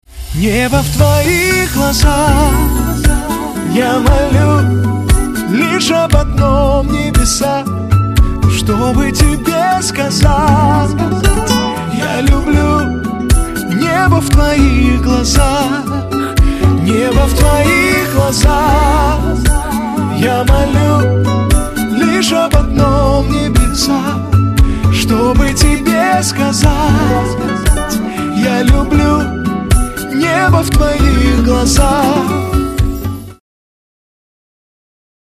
• Качество: 320, Stereo
поп
гитара
инструментальные